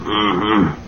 Ummmm huuuuuh Sound Effect
ummmm-huuuu.mp3